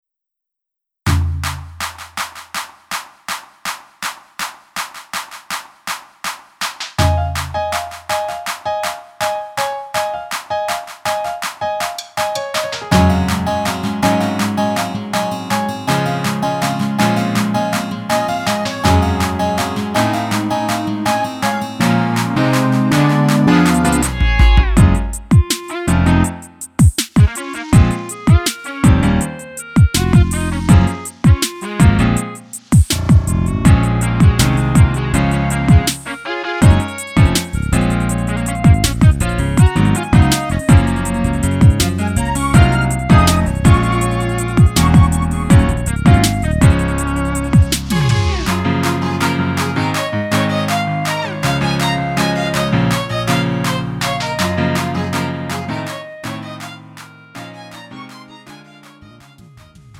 음정 -1키 3:42
장르 가요 구분